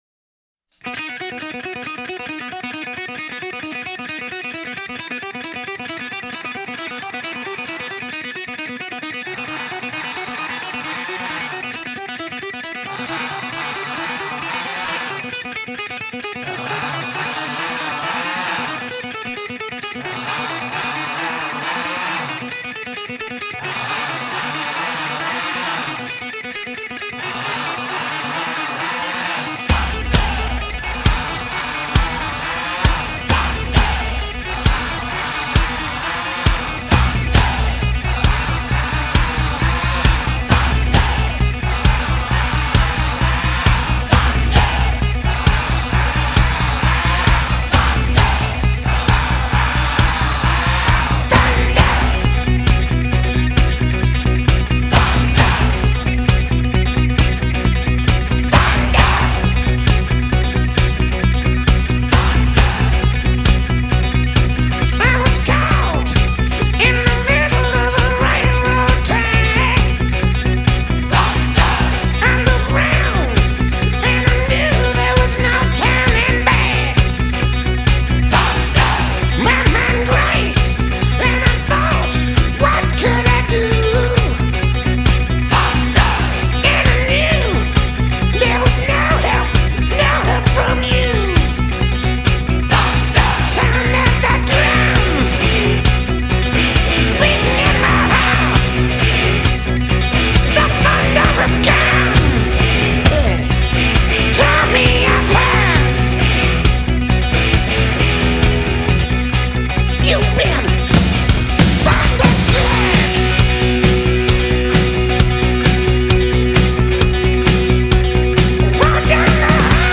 rock
рок-музыка